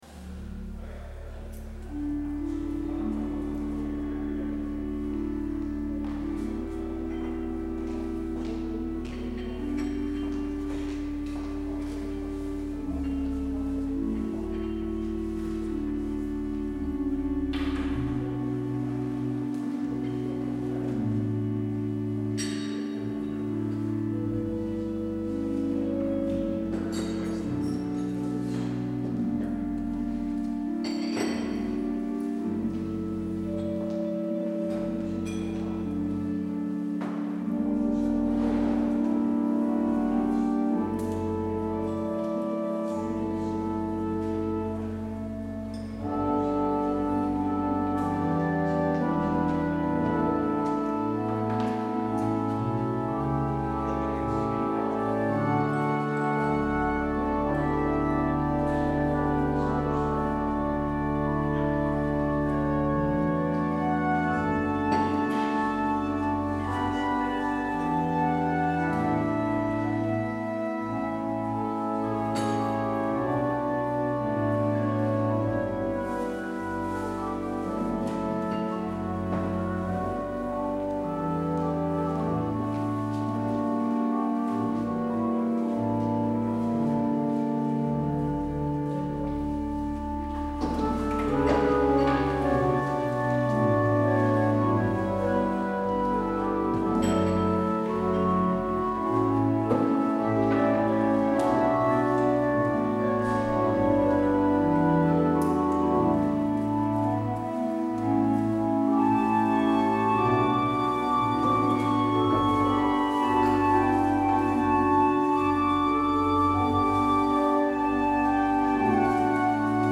 Luister deze kerkdienst terug
Als openingslied: Lied 303 Zonne en maan en als slotlied: Lied 981 Zo lang er mensen zijn op aarde.